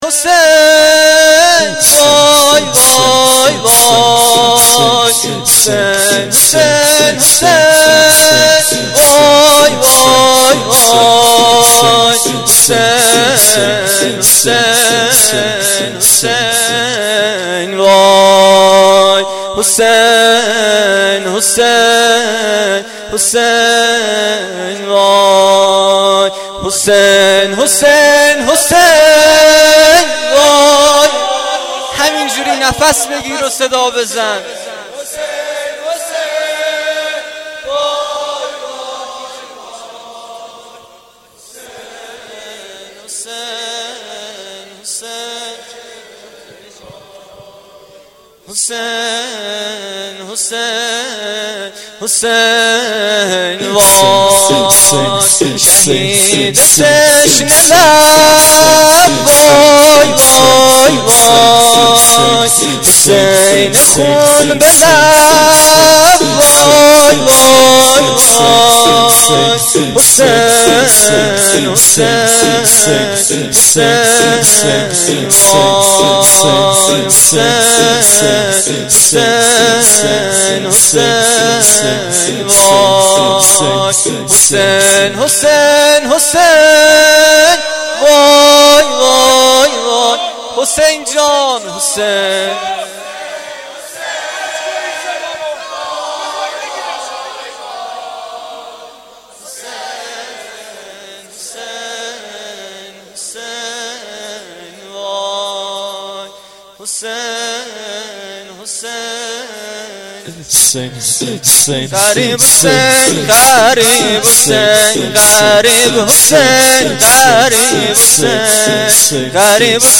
شور شام غریبان محرم1393